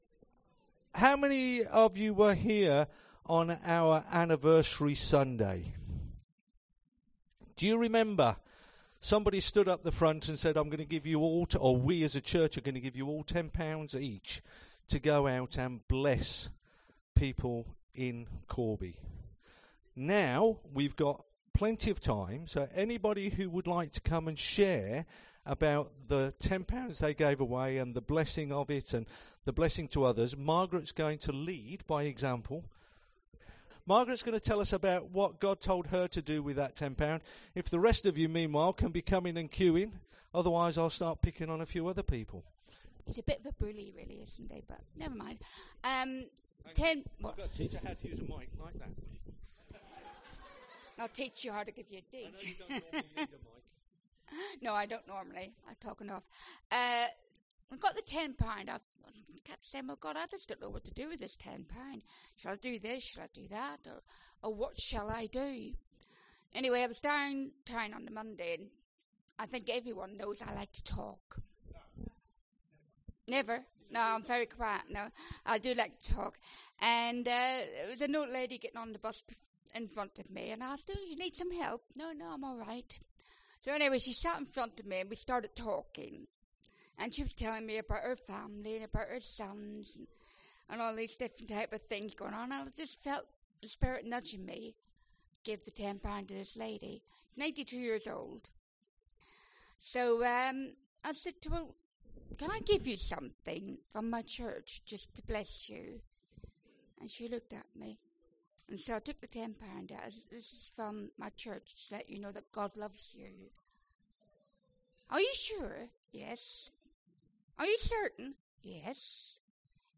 Today, we hear testimonies from a number of people on how they distributed their gifts.